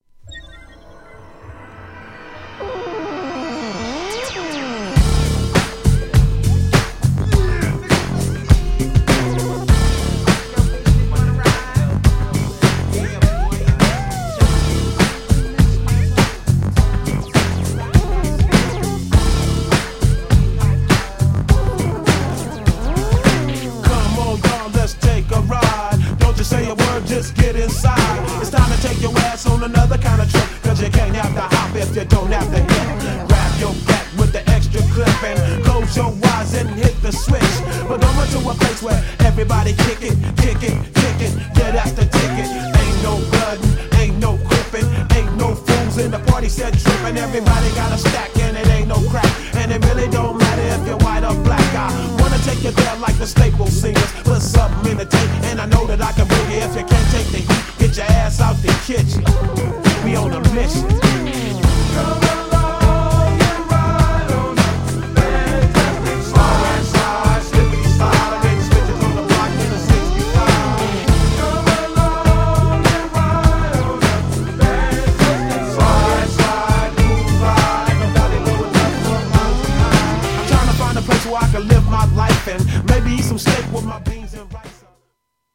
大ネタ使いの正にG-FUNKなウェッサイBIG HIT!!
GENRE Hip Hop
BPM 106〜110BPM
ファンクなHIPHOP # ベースうねってますけど